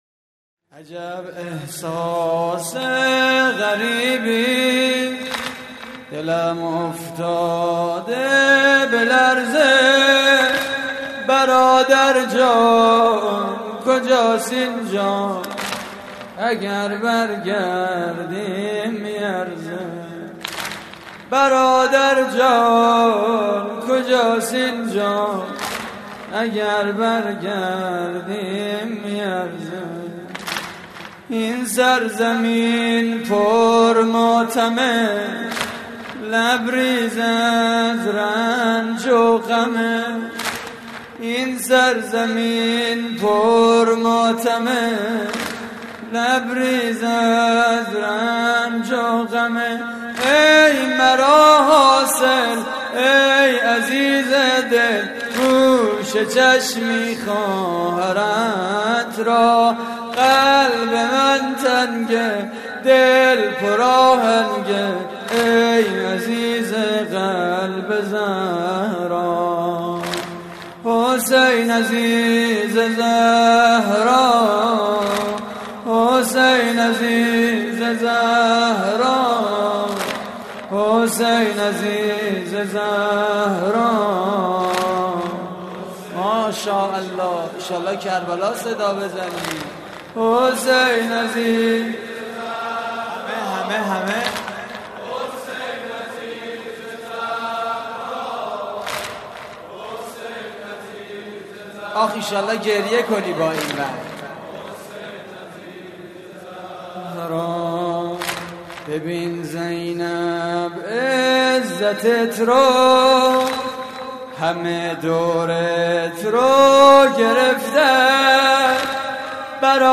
واحد: این سرزمین پر ماتمه
مراسم عزاداری شب دوم ماه محرم